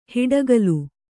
♪ hiḍagalu